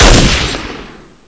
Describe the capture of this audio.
PSP/CTR: Also make weapon and zombie sounds 8bit